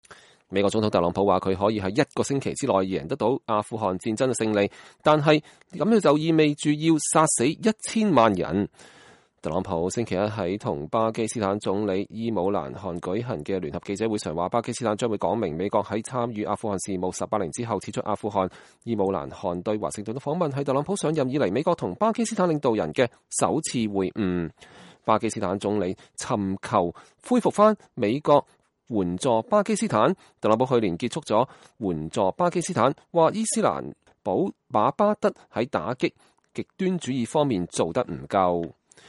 美國總統特朗普說，他可以在“一個星期”內贏得阿富汗戰爭的勝利，但是這意味著要殺死1千萬人。特朗普星期一在與巴基斯坦總理伊姆蘭·汗舉行的聯合記者會上說，巴基斯坦將幫助美國在參與阿富汗事務18年後撤出阿富汗。